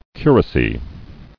[cu·ra·cy]